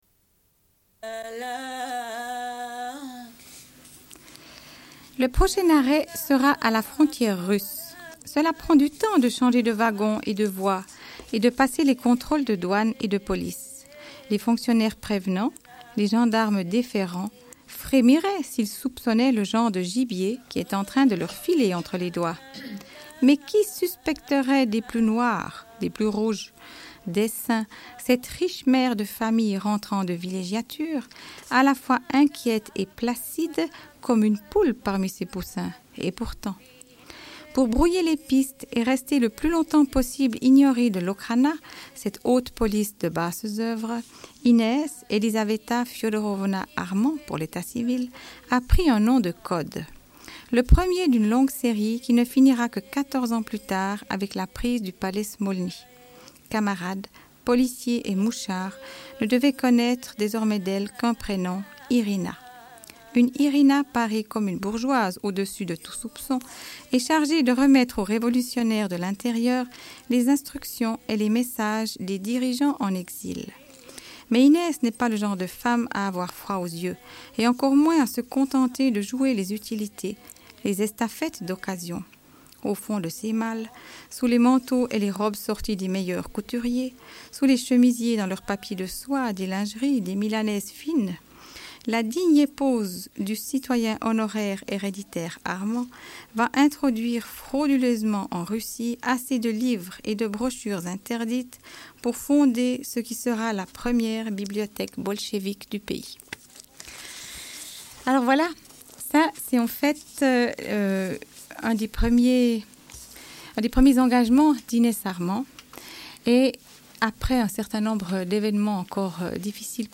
Une cassette audio, face B28:51